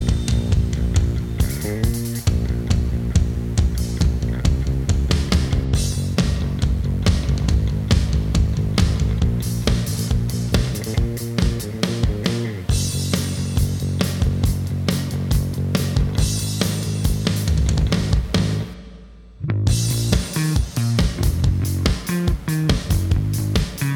No Guitars Pop (2010s) 3:19 Buy £1.50